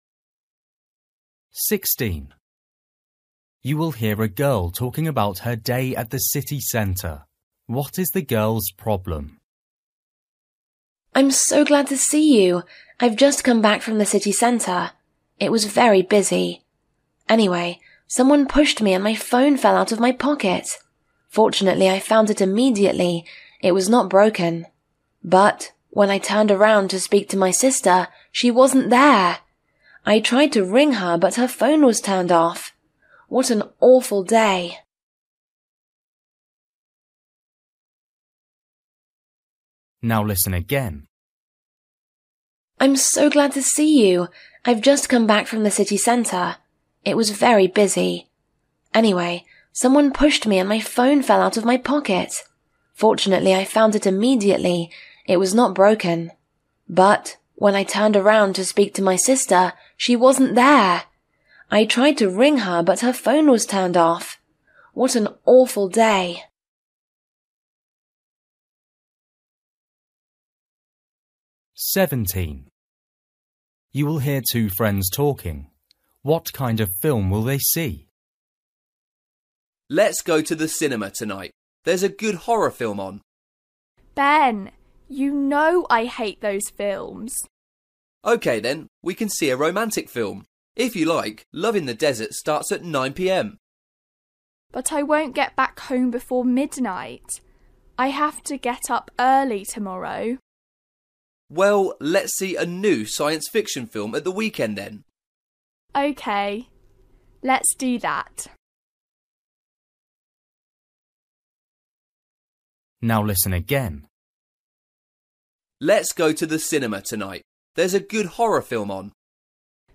Listening: everyday short conversations
16   You will hear a girl talking about her day at the city centre.
17   You will hear two friends talking.
19   You will hear a teacher talking.
20   You will hear a mother and a son talking.